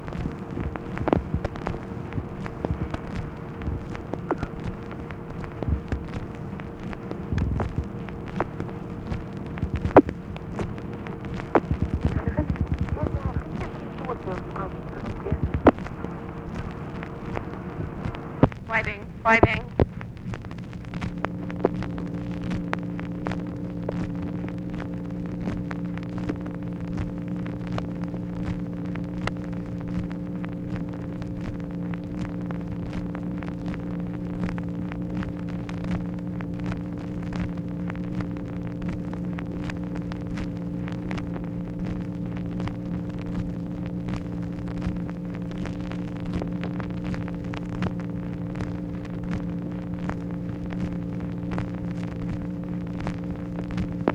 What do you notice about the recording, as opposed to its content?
Secret White House Tapes | Lyndon B. Johnson Presidency